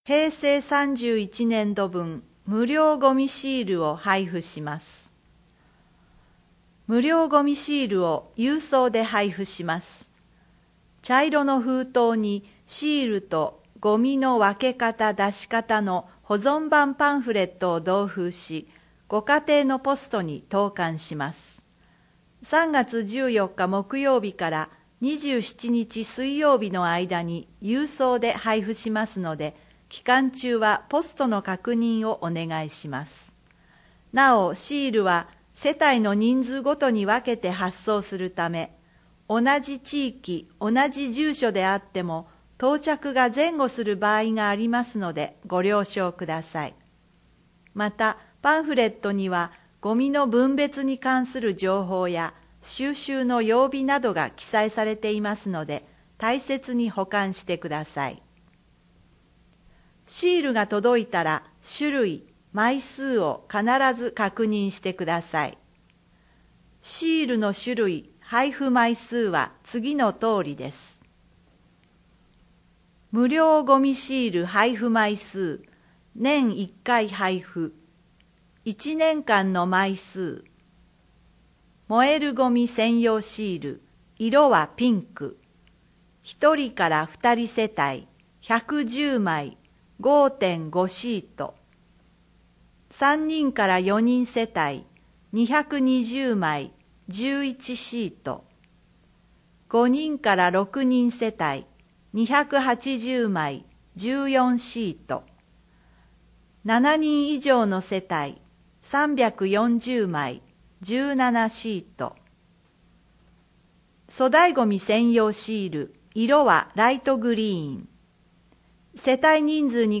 本市の依頼により富田林市朗読ボランティアグループ「くさぶえ」が視覚に障がいをお持ちの人などのために製作し、貸し出しているテープから抜粋して放送しています。